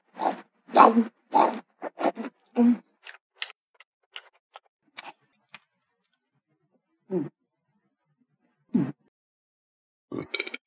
Snarfing
belch burp cartoon eat female funny human lips sound effect free sound royalty free Funny